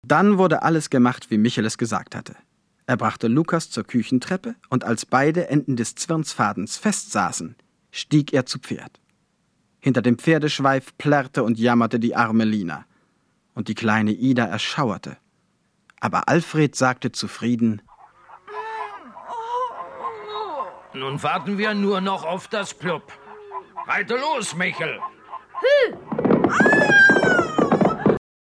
Hörbuch: Michel aus Lönneberga 2.
Hörspielklassiker Astrid Lindgren